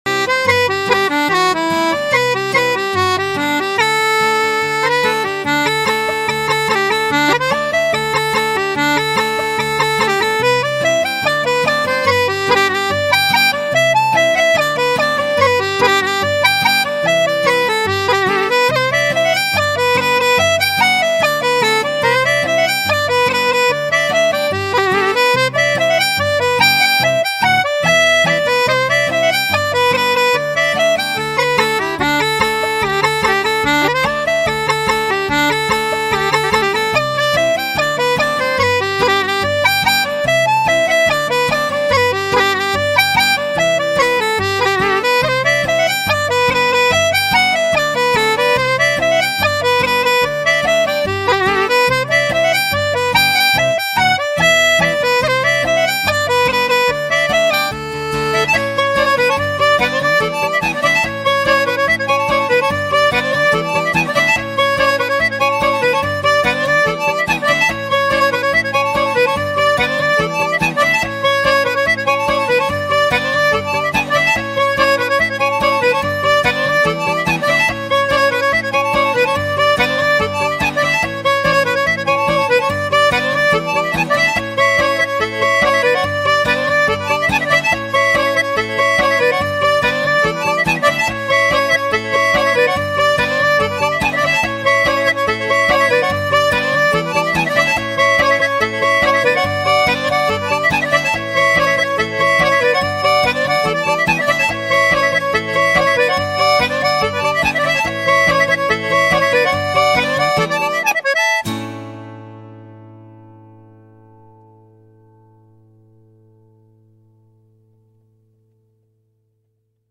(Instrumental) 🎵 AI Generated Music